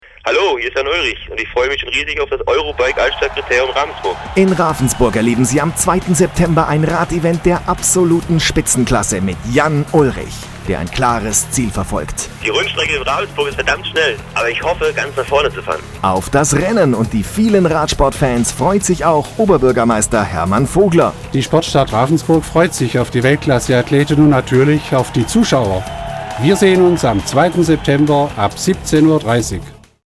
29.08.2005 | Radiospot mit Jan Ullrich auf SWR1 zu hören
Zum 2. EUROBIKE Altstadtkriterium in Ravensburg gibt es wieder ein Radiospot mit Jan Ullrich täglich auf SWR1 zu hören.